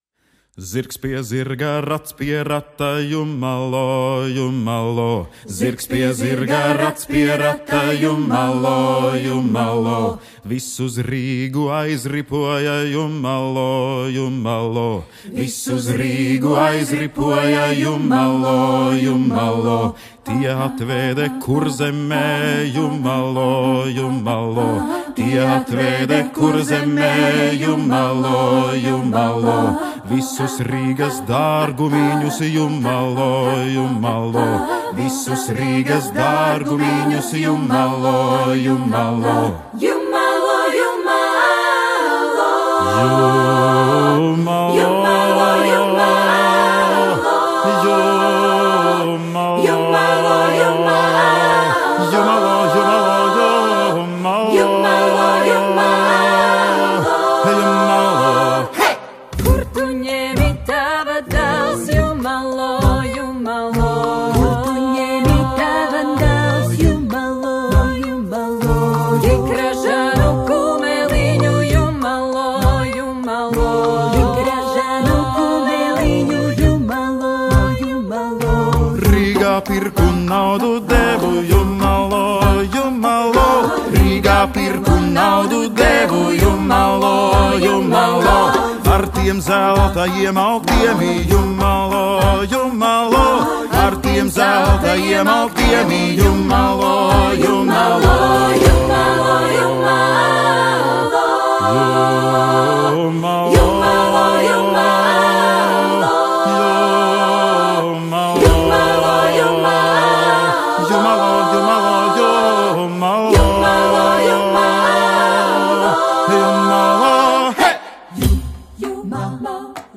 Voicing: SSAATTB and Percussion